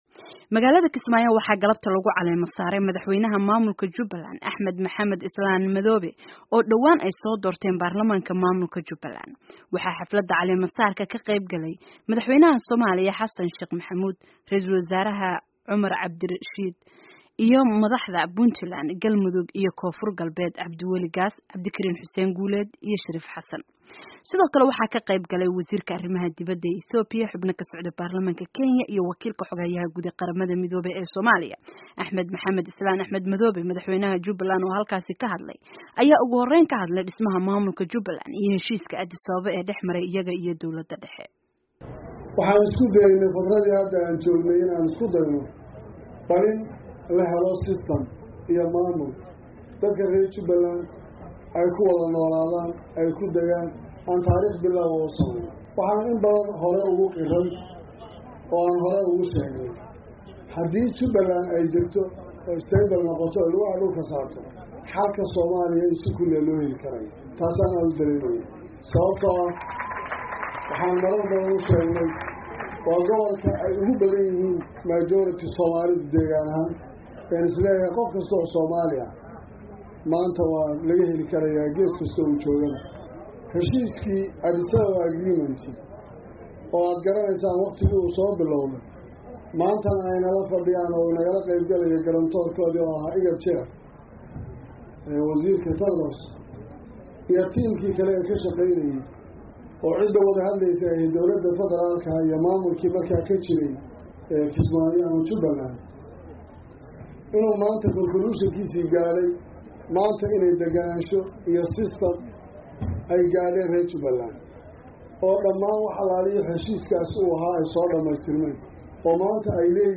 Magaalada Kismaayo waxaa galabta lagu caleemo-saaray madaxweynaha maamulka Jubbaland, Axmed Maxamed Islaam “Madoobe” oo dhawaan ay soo doorteen baarlamaanka maamulka Jubbaland.